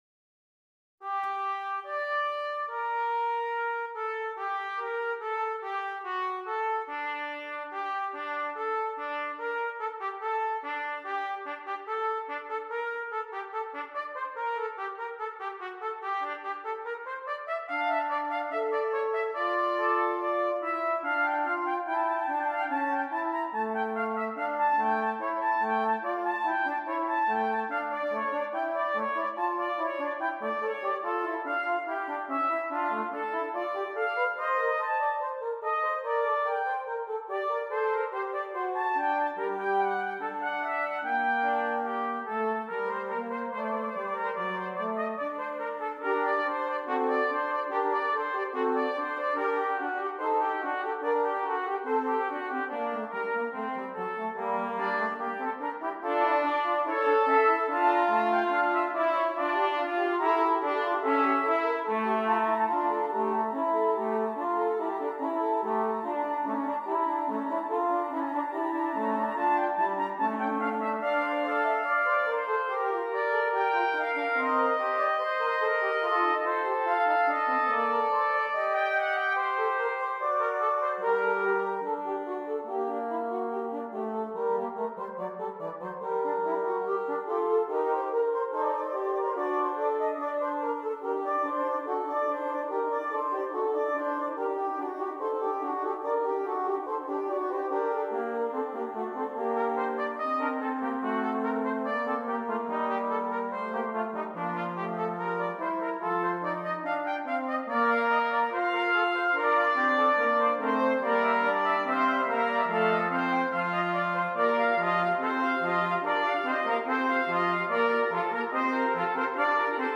6 Trumpets